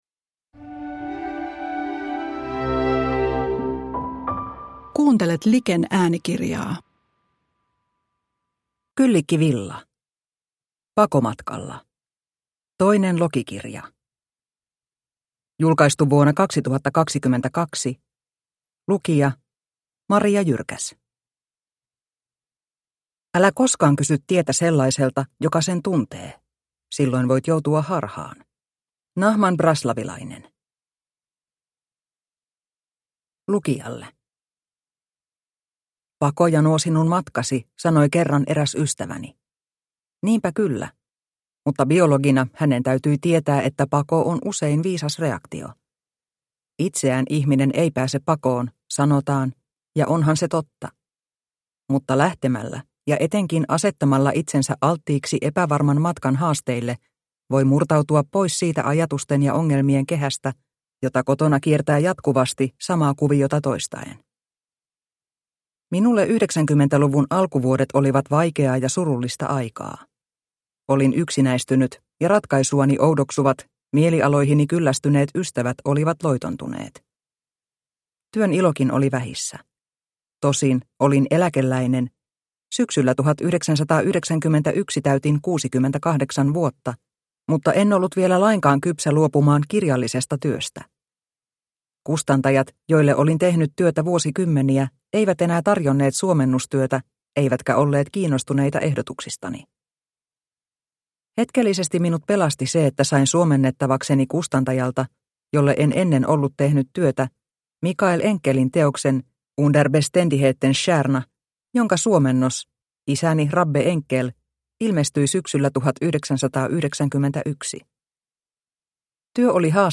Pakomatkalla - toinen lokikirja – Ljudbok